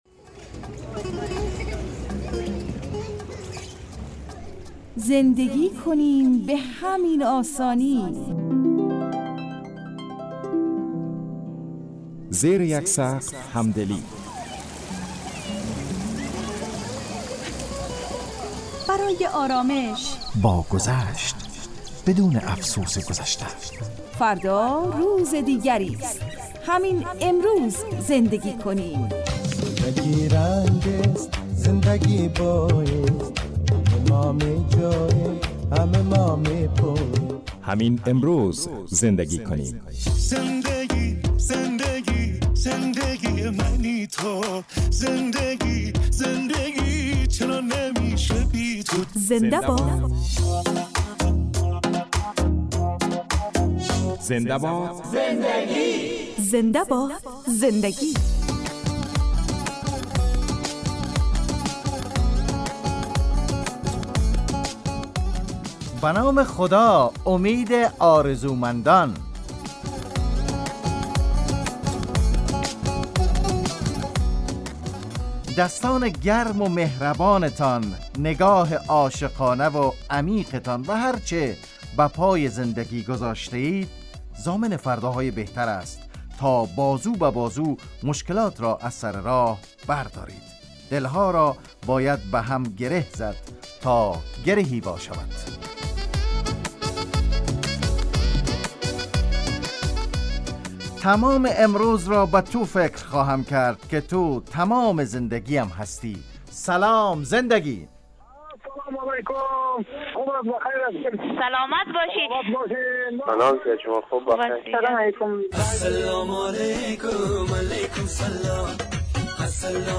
برنامه خانوادگی رادیو دری